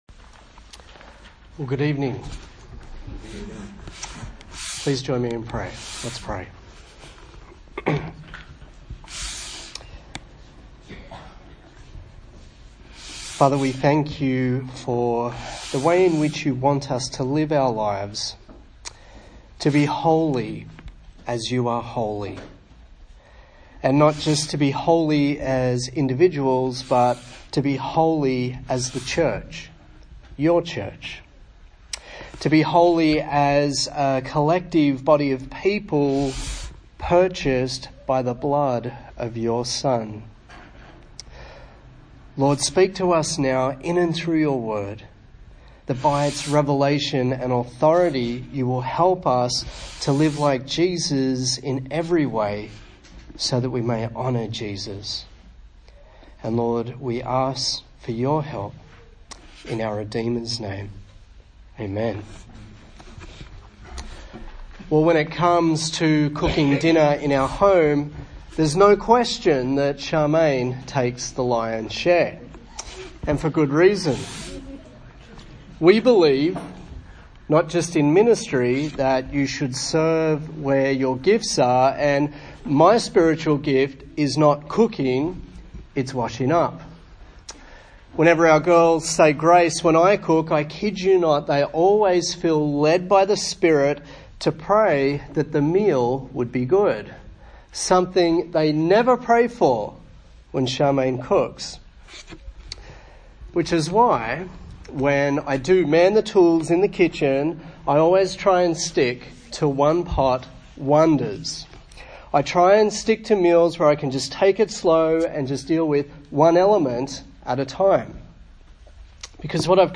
A sermon in the series on the book of 1 Thessalonians